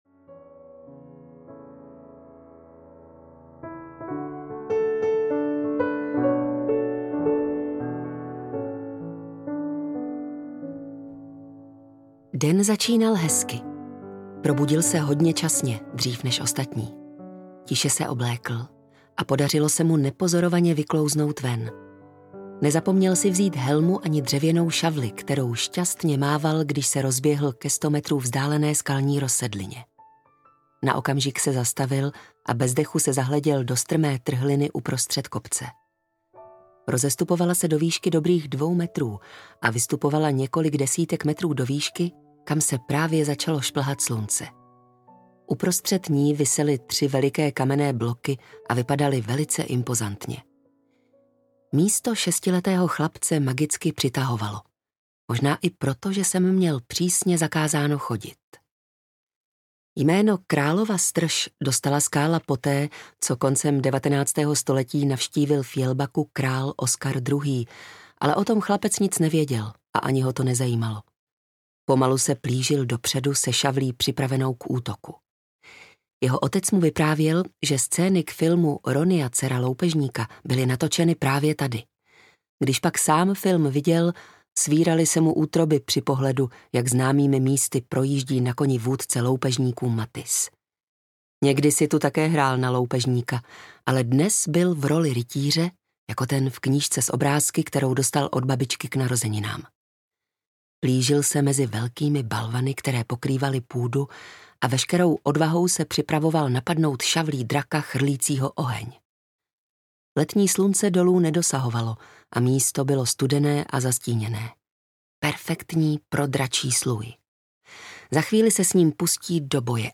Kazatel audiokniha
Ukázka z knihy